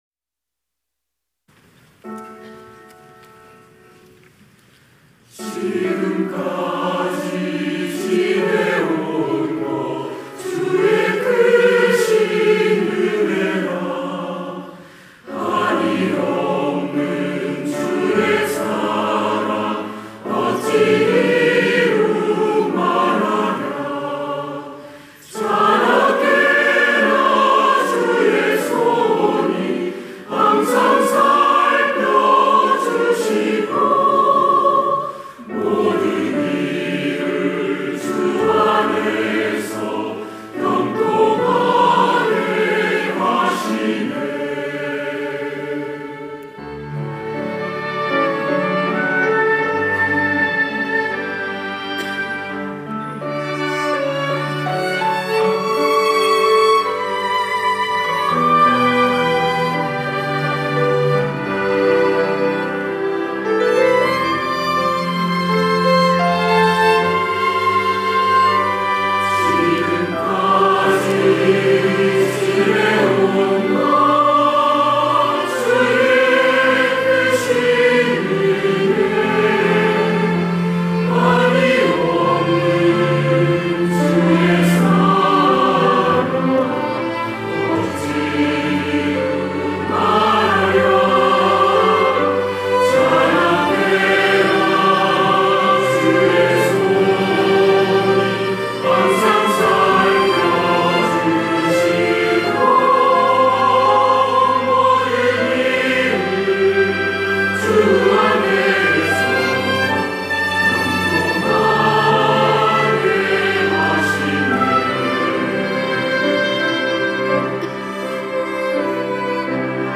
할렐루야(주일2부) - 지금까지 지내온 것
찬양대